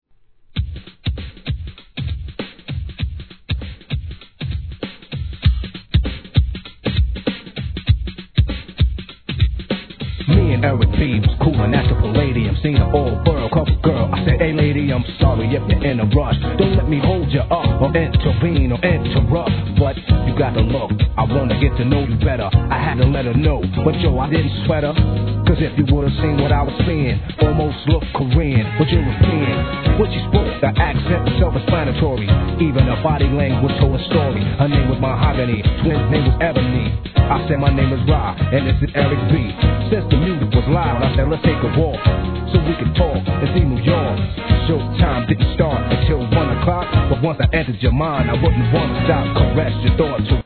1. HIP HOP/R&B
終盤のPIANOなんかもムーディー♪